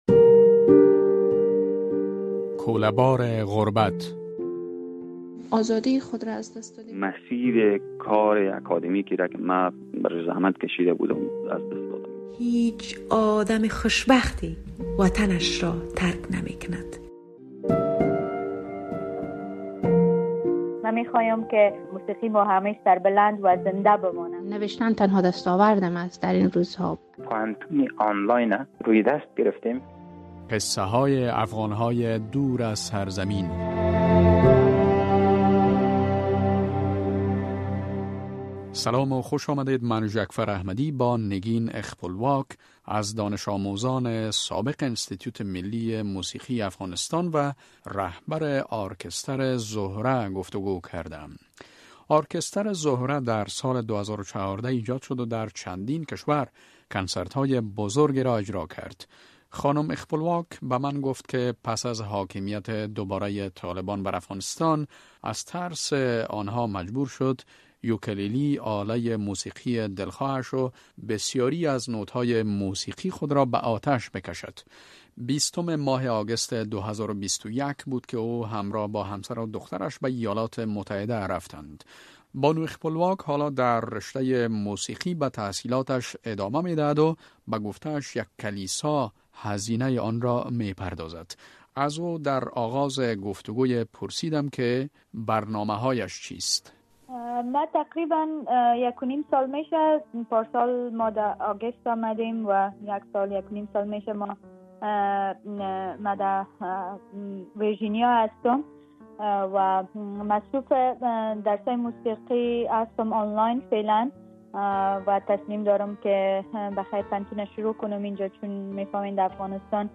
رادیو آزادی سلسله ای از گفت و گو های جالب با آن عده از شهروندان افغانستان را آغاز کرده است که پس از حاکمیت دوبارۀ طالبان بر افغانستان، مجبور به ترک کشور شده اند.